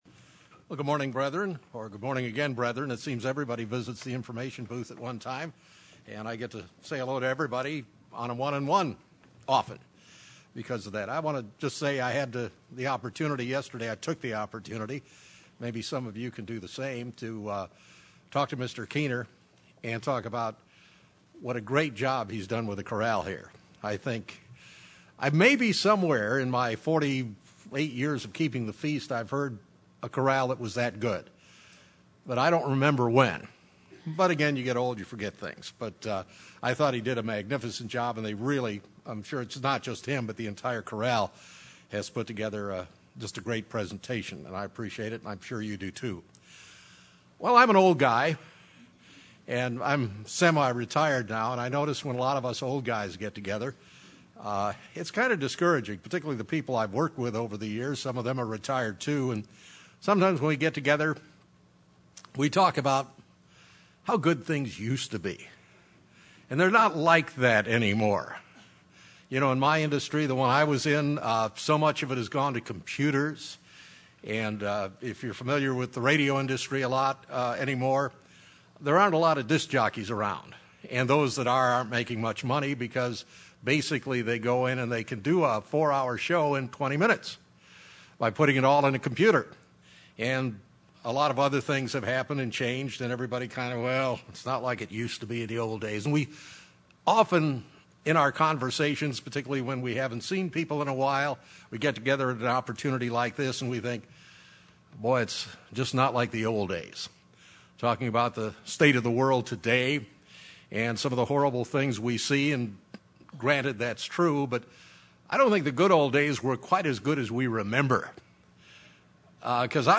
This sermon was given at the Branson, Missouri 2013 Feast site.